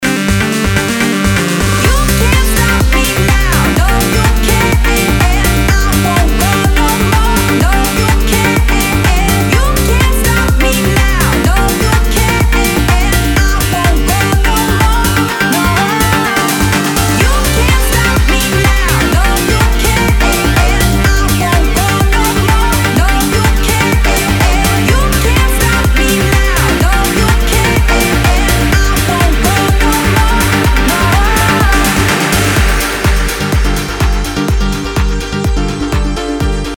dance
house
электронные